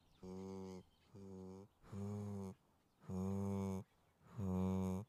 Тихий стон или мычание теленка